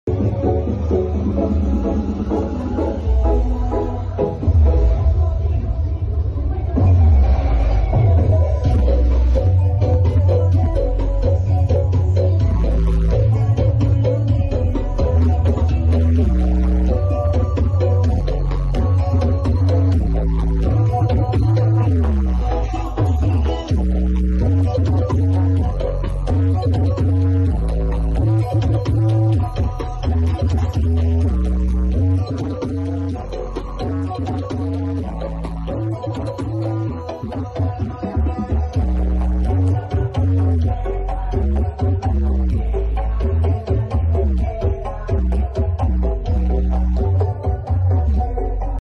Ngosex sruk brutal karnaval arjowilangun